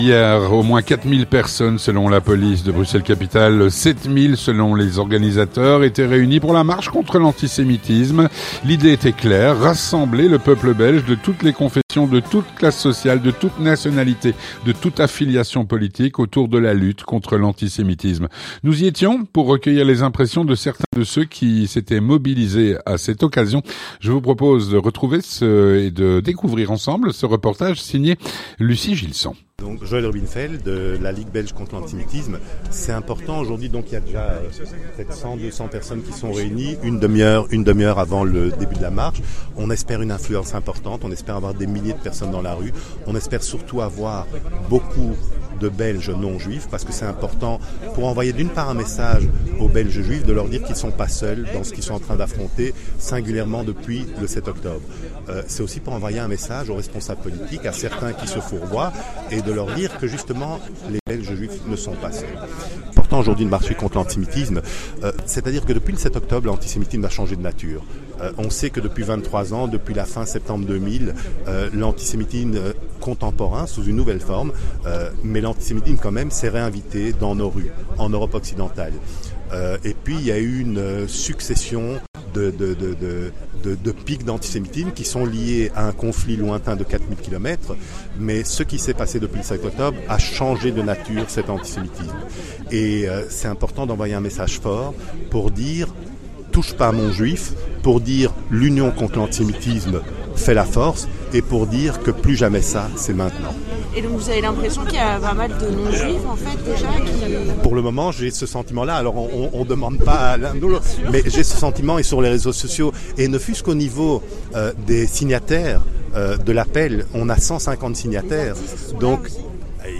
Édition Spéciale - Au coeur de la manifestation nationale contre l'Antisémitisme le 10 décembre. (11/12/2023)
Nous y étions, pour recueillir les impressions de certains de ceux qui s'étaient mobilisés à cette occasion.